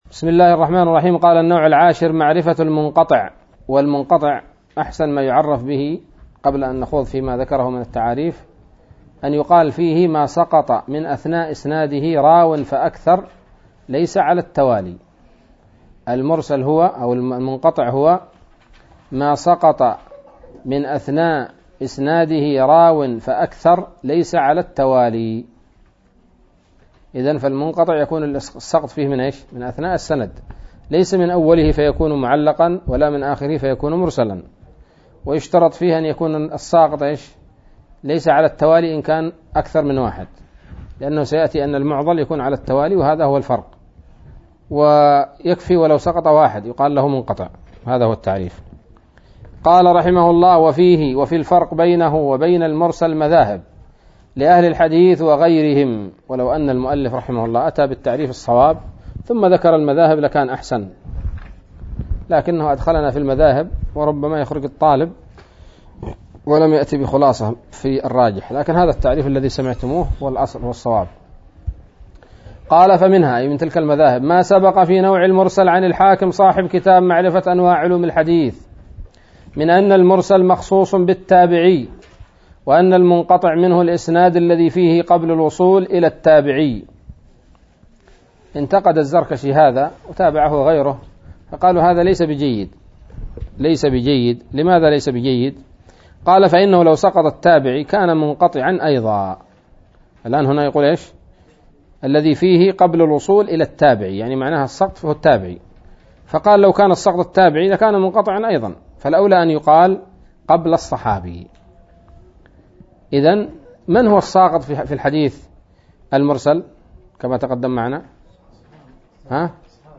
الدرس الخامس والعشرون من مقدمة ابن الصلاح رحمه الله تعالى